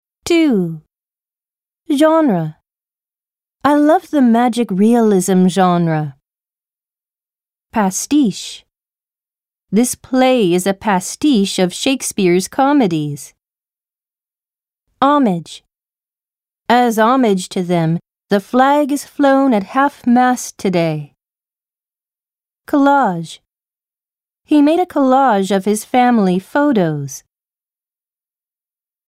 ・ナレーター：アメリカ英語のネイティブ１名（女性）
・スピード：ちょうどよく、聞き取りやすい